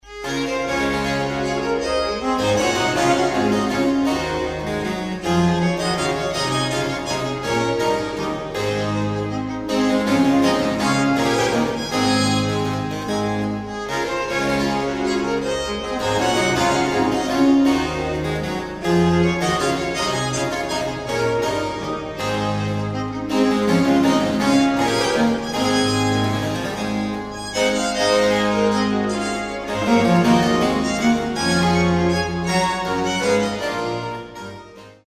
Baroque chamber ensembles
suites (6) for violin, 2 violas & continuo